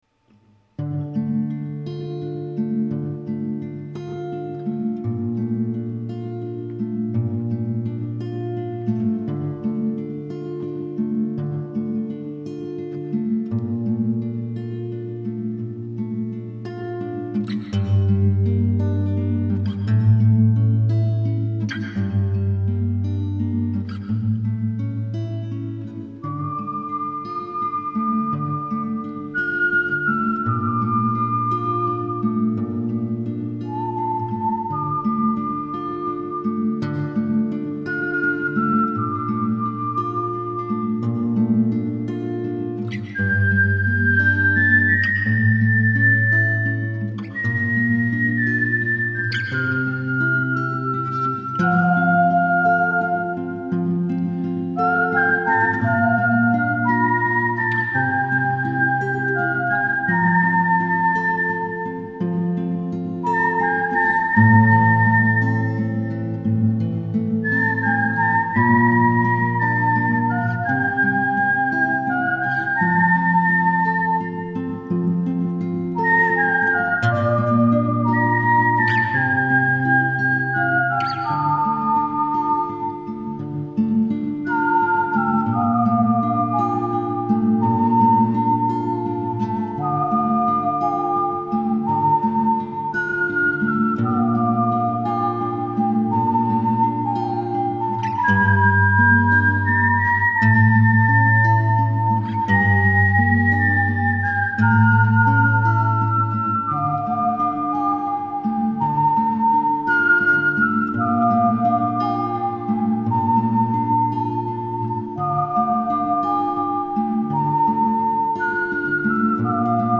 Ode to My Dad He actually commisioned this song.. He insisted that I was such a good whistler, that he'd give me $50 for a song with whistling in it.